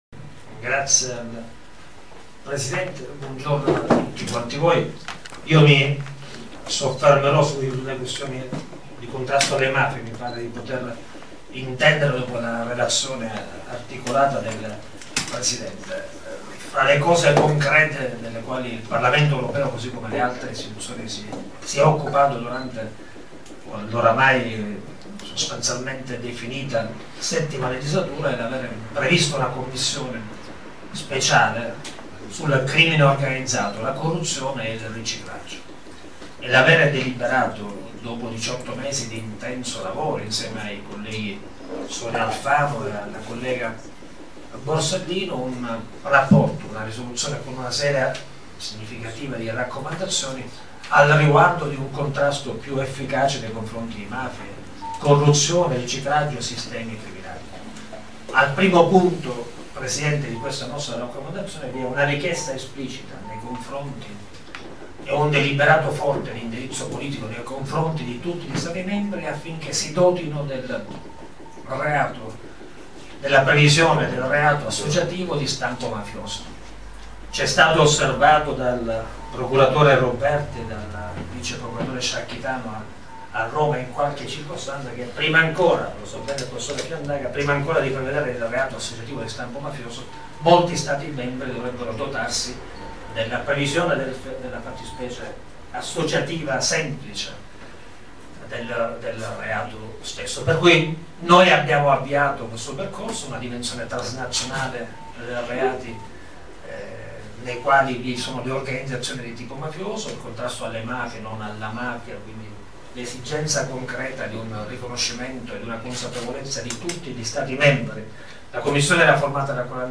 FORUM CON I CANDIDATI ALLE ELEZIONI EUROPEE 2014
CENTRO STUDI PIO LA TORRE
PALERMO